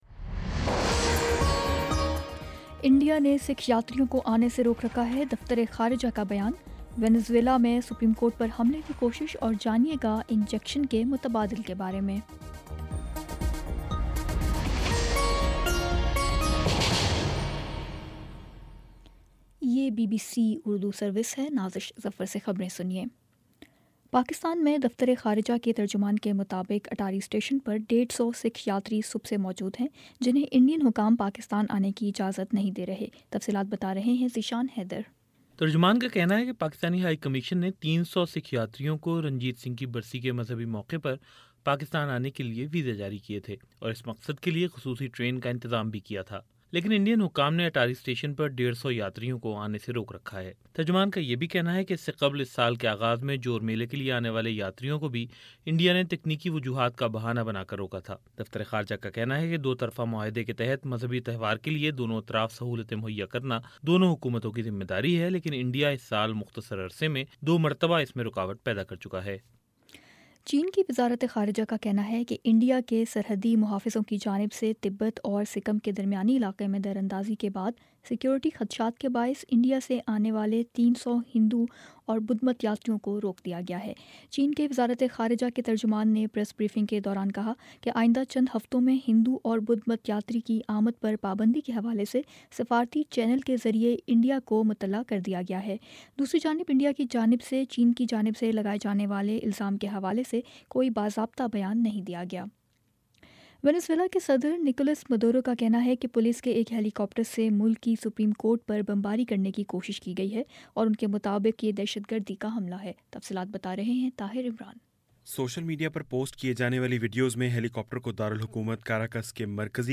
جون 28 : شام چھ بجے کا نیوز بُلیٹن